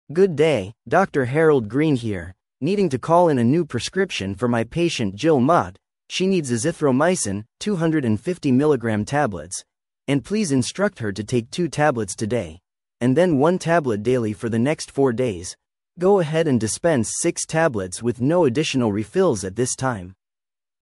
Practice Taking Verbal Prescriptions